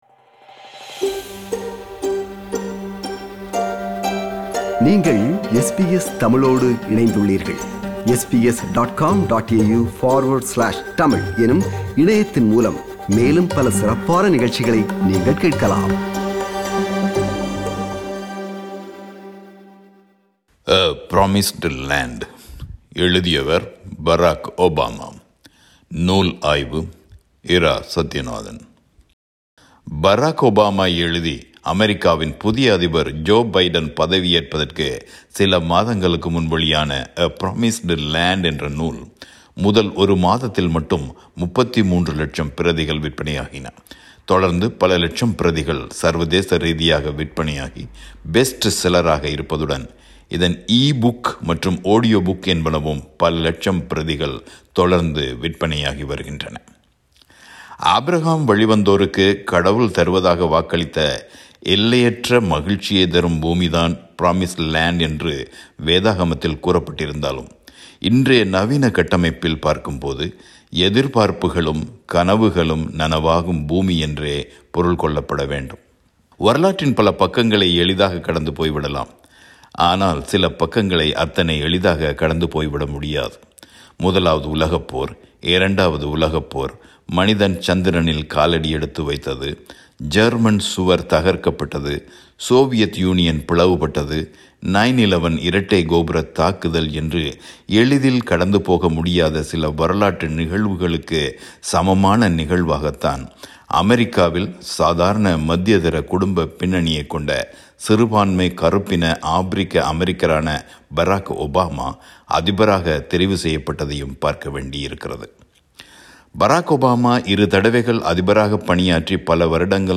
உலக அரசியல் பதட்ட வேளைகளில் தான் என்ன செய்தேன் என்று ஒபாமா இந்த நூலில் விளக்குகிறார். பல லட்சம் பிரதிகள் விற்பனையாகியுள்ள இந்த நூல் குறித்த விமர்சனம்.